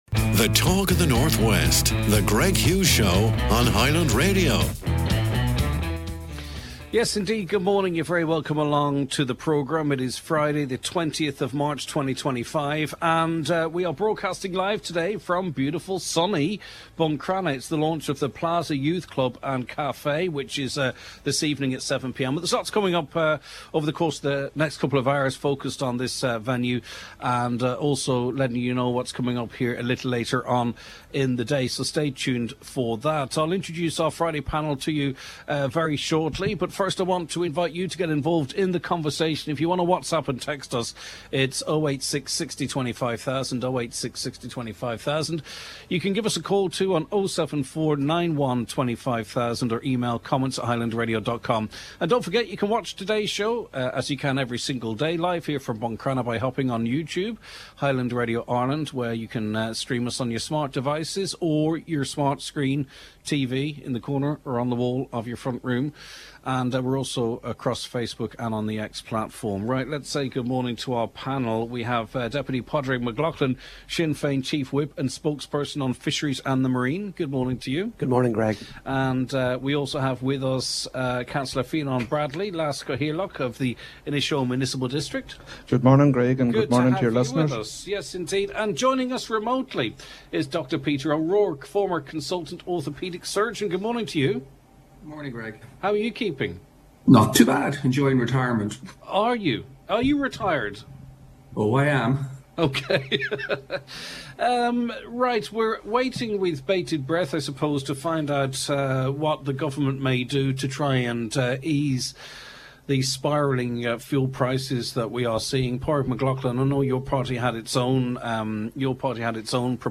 Live Music: The atmosphere is electric with live performances in the café from The 2 Bucks .